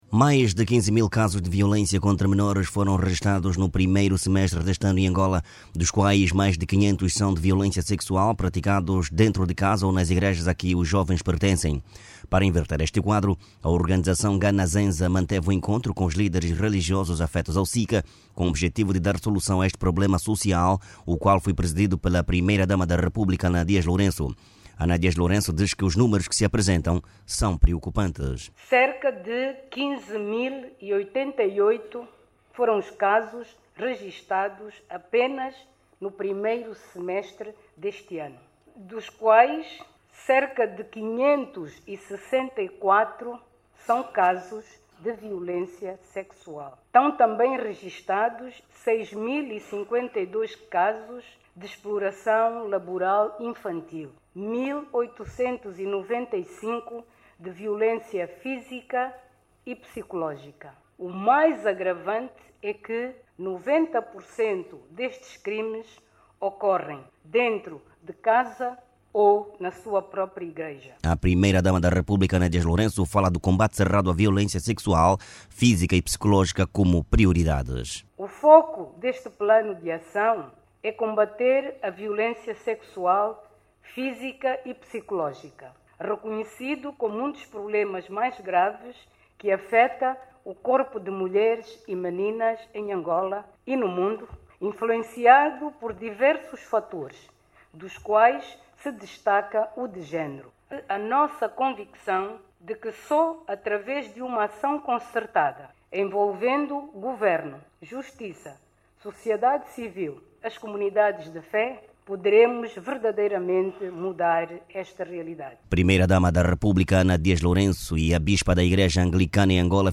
NOTÍCIAS